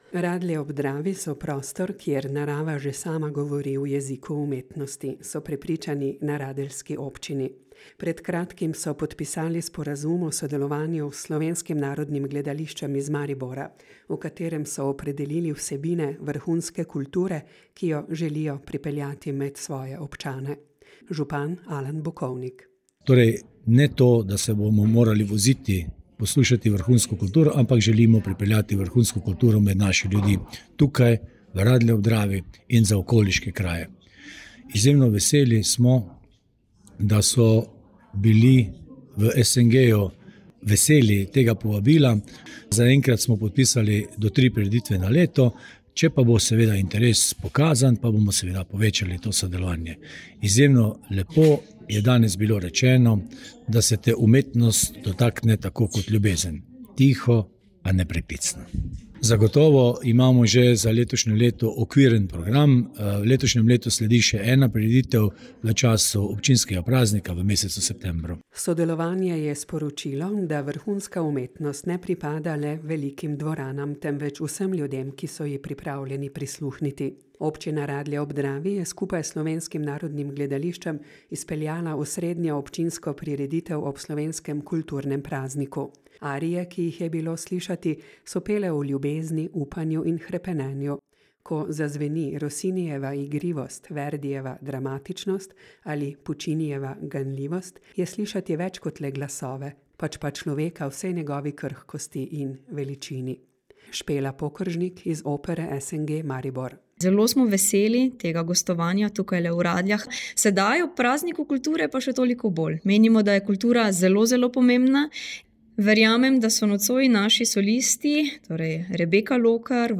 Pevci iz SNG Opera in bale so peli arije o ljubezni, upanju in hrepenenju.